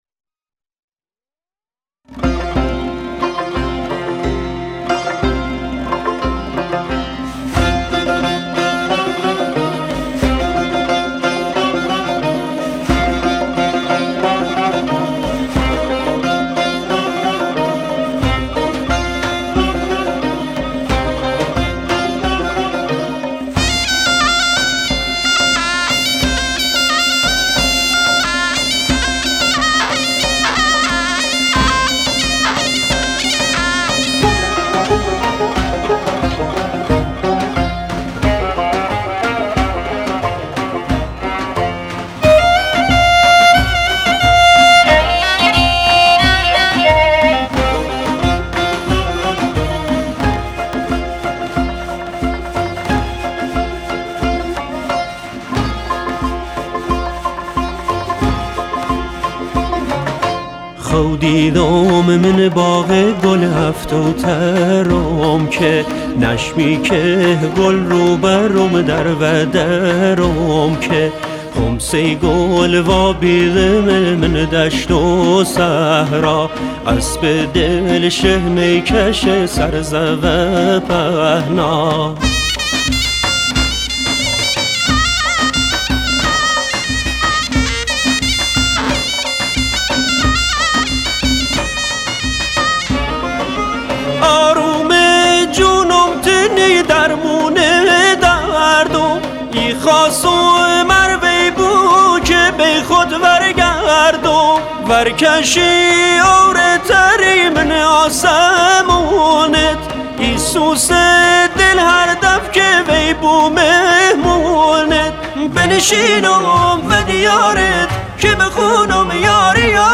آهنگ شاد لری